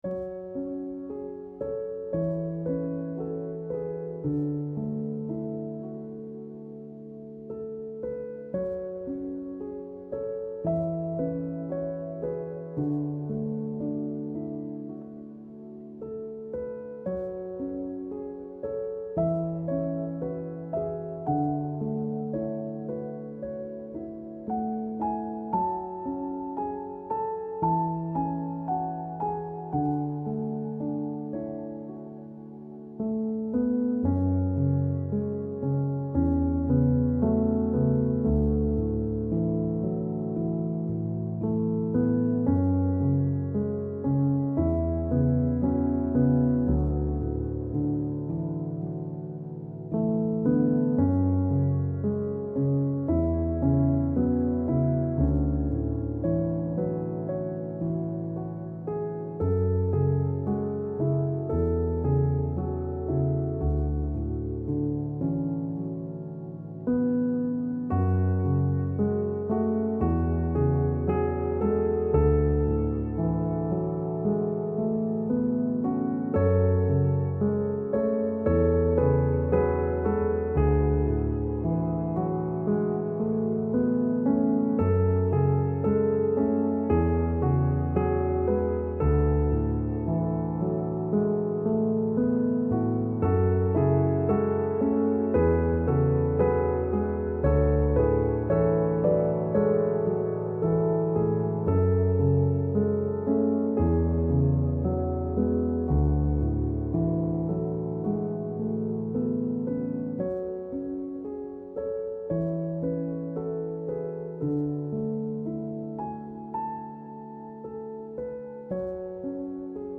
سبک آرامش بخش , پیانو , مدرن کلاسیک , موسیقی بی کلام